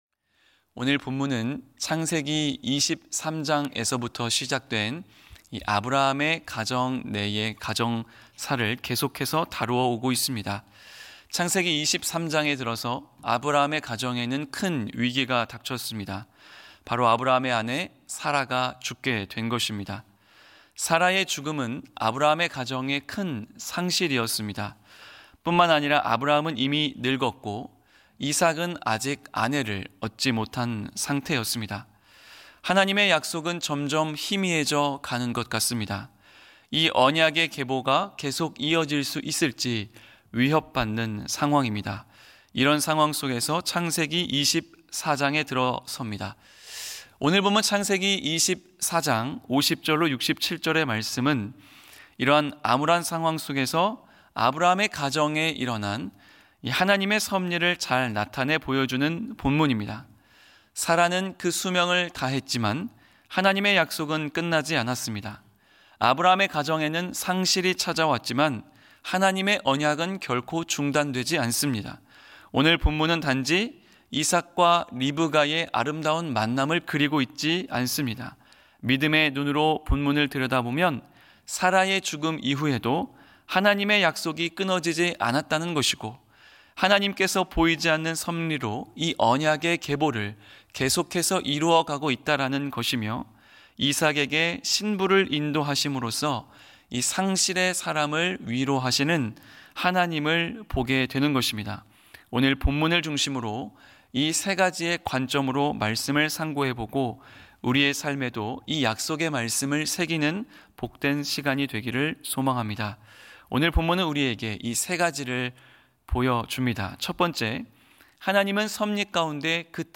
예배 새벽예배